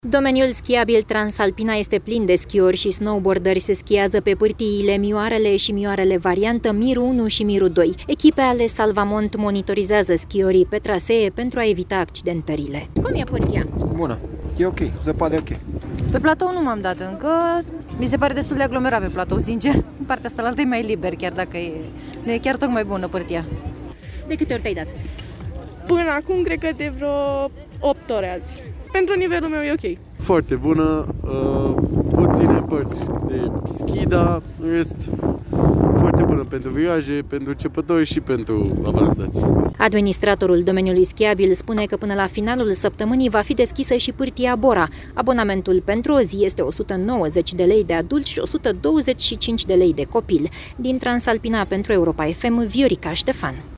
Un alt turist a declarat că pârtia este „foarte bună”, deși există „puține părți de schi, dar în rest foarte bună pentru viraje, pentru începători, dar și pentru avansați”.